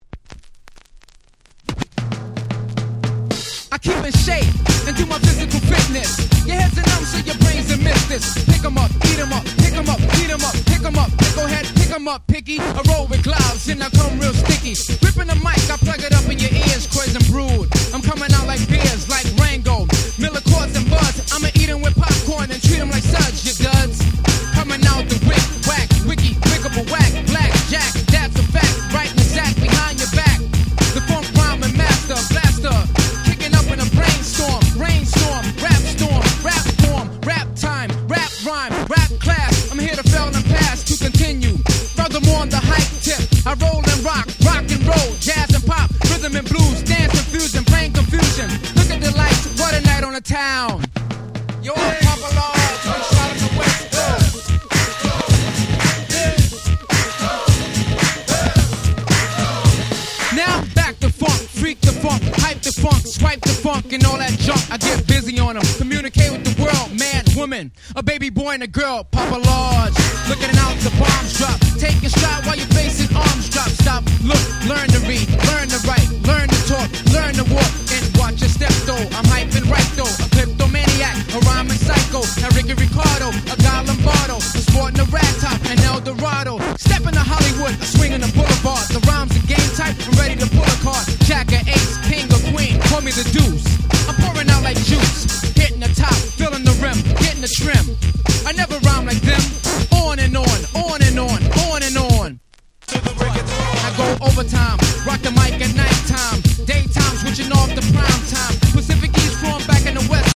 B-Boy大歓喜のEarly 90's Hip Hop Classics !!
もう男汁全開で手の付け様がございません。